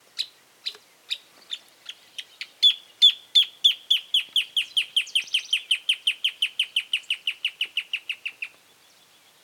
Voz – Aves Argentinas
Voz
espinero-grnade_voz.mp3